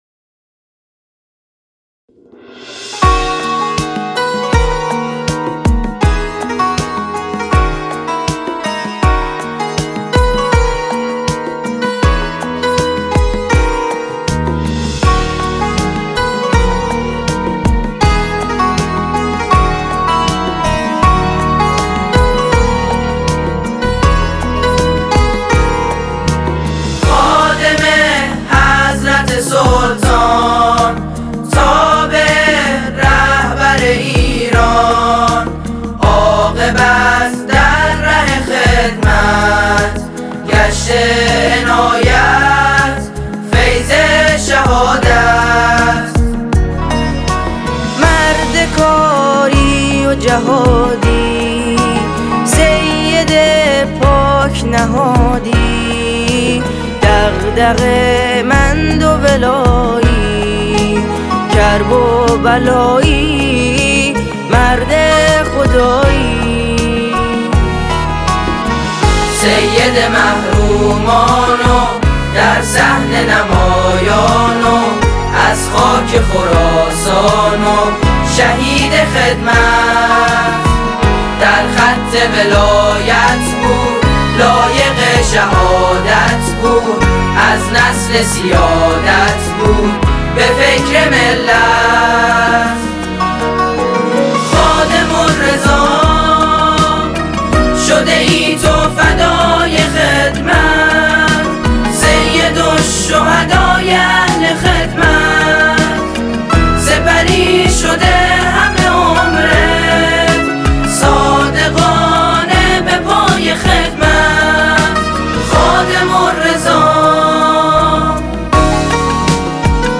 سرود حماسی و رثایی
ژانر: سرود